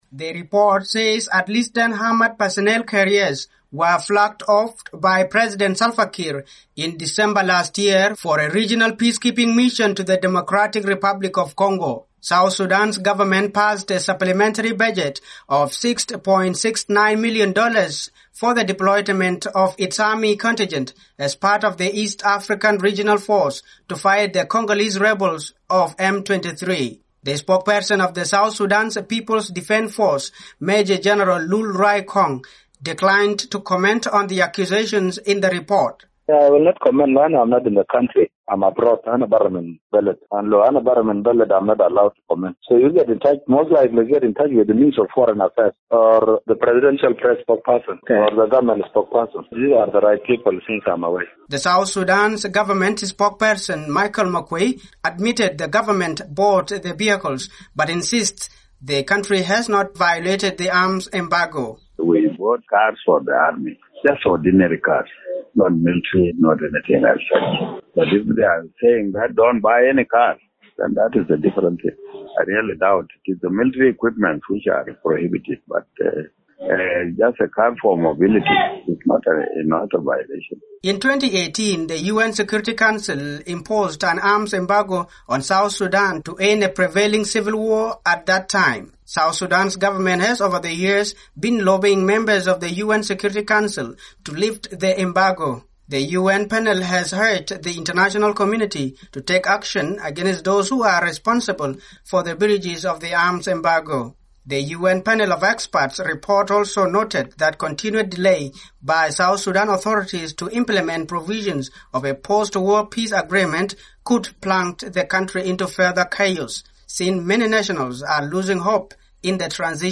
A new report by a United Nations Panel of Experts on South Sudan has accused Juba of breaching an arms embargo imposed on the country in 2018. South Sudan’s minister of information and government spokesperson, Michael Makuei admits his government bought military vehicles but insists it did not violate the arms embargo.